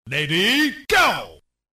拳皇游戏ready go配音音效免费音频素材下载